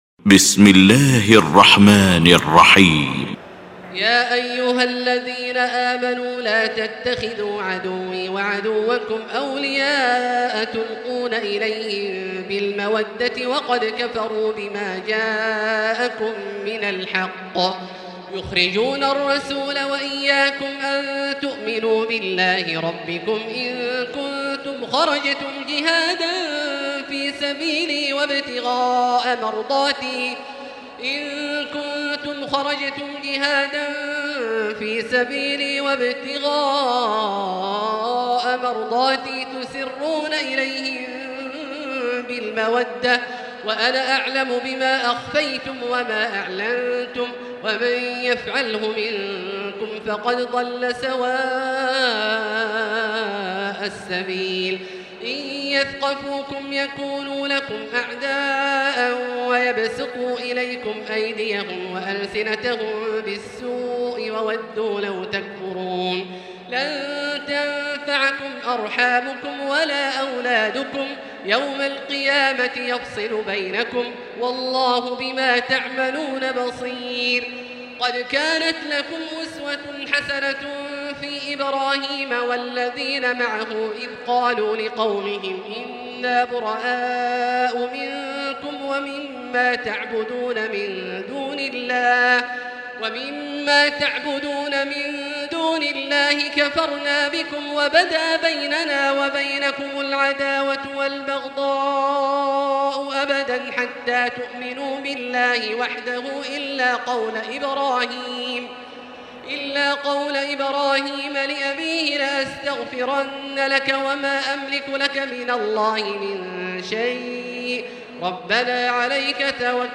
المكان: المسجد الحرام الشيخ: فضيلة الشيخ عبدالله الجهني فضيلة الشيخ عبدالله الجهني الممتحنة